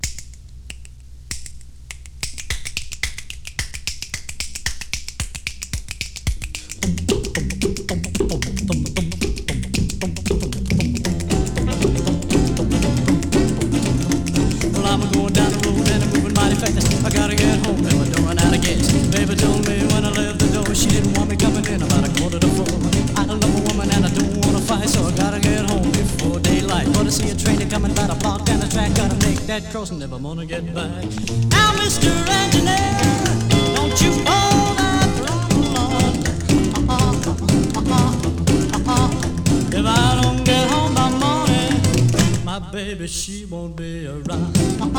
Rock'N'Roll, Rockabilly　UK　12inchレコード　33rpm　Mono